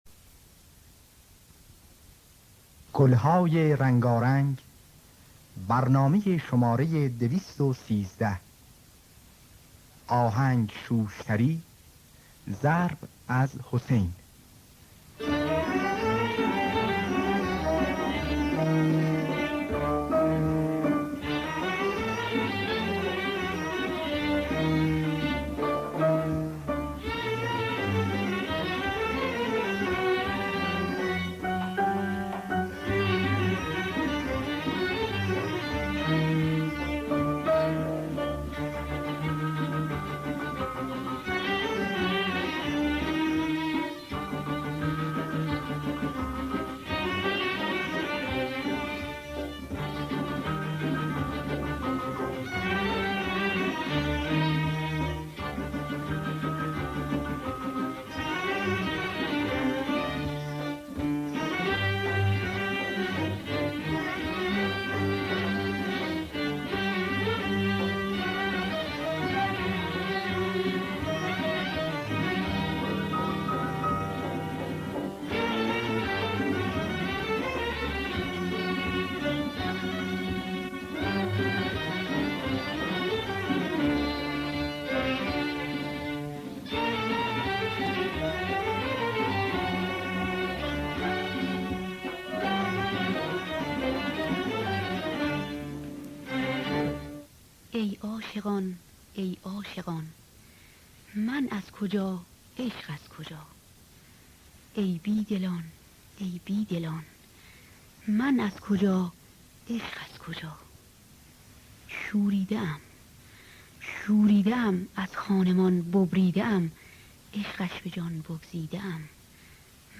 ویولن
تنبک / ضرب
دستگاه: همایون